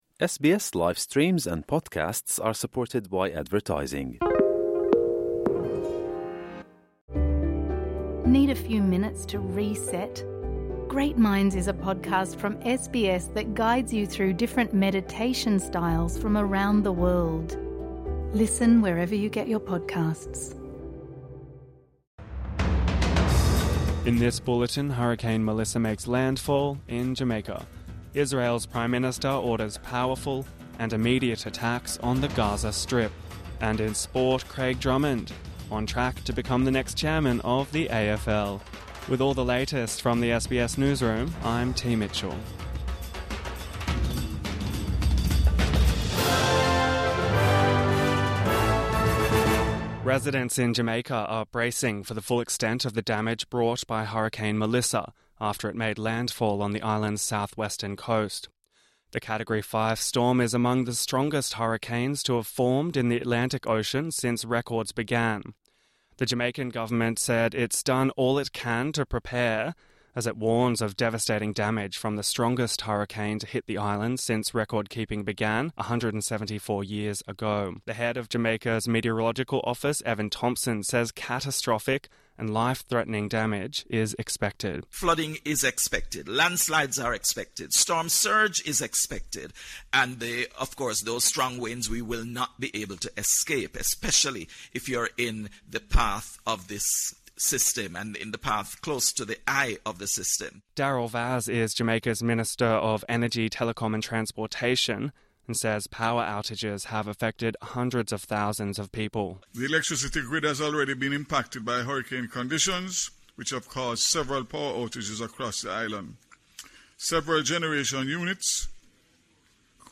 Morning News Bulletin 29 October 2025